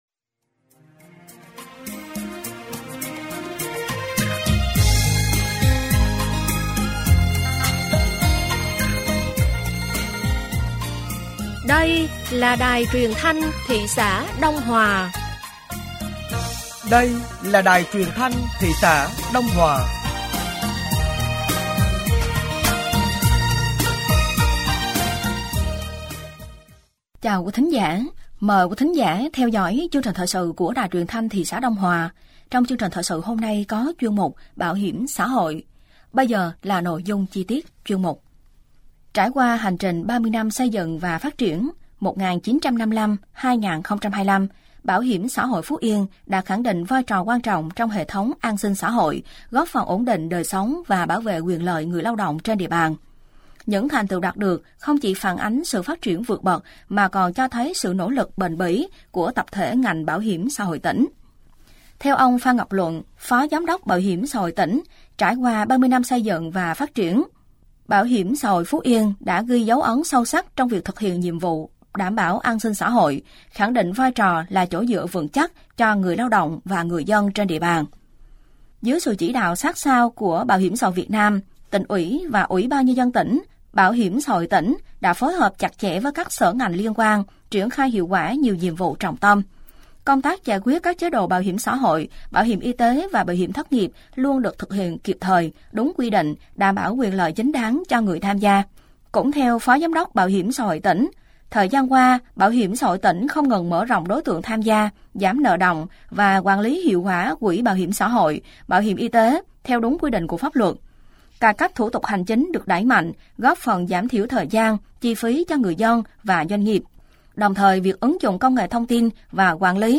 Thời sự tối ngày 09 và sáng ngày 10 tháng 3 năm 2025